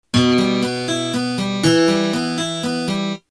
Способы аккомпанимента перебором
Em (6/8)